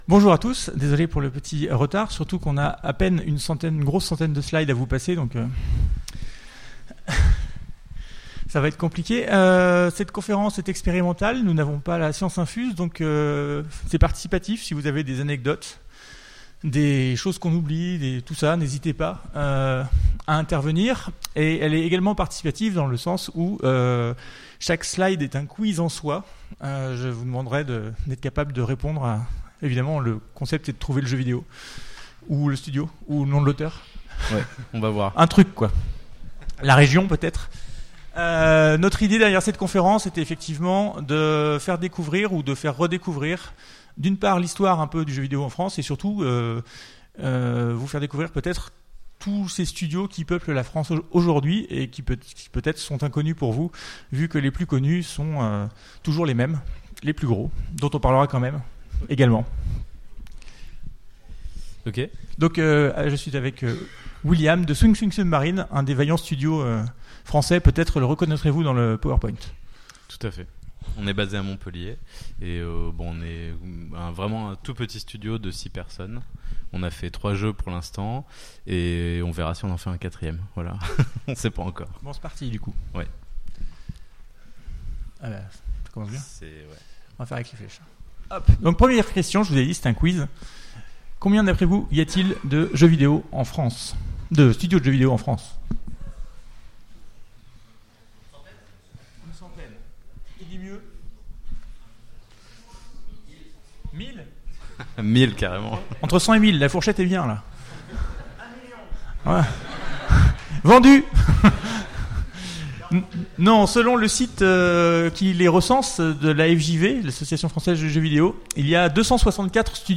Utopiales 2017 : Conférence Retour vers le jeu vidéo français